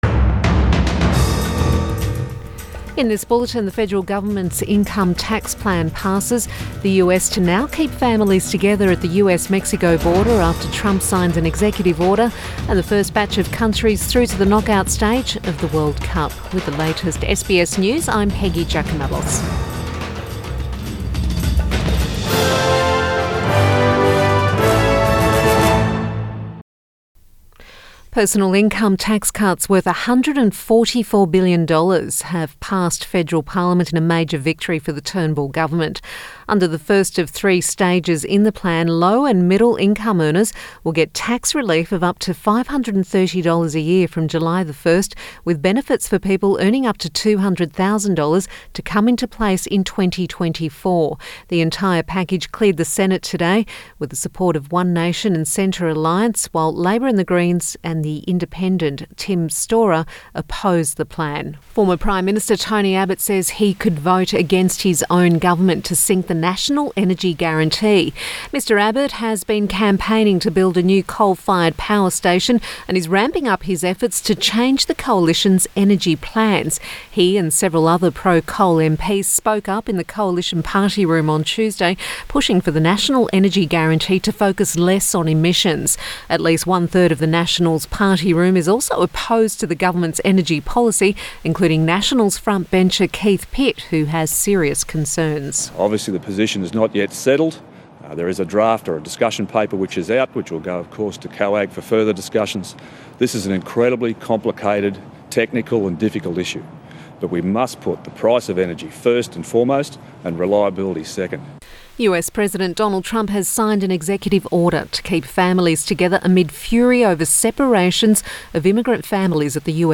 Midday Bulletin 21 June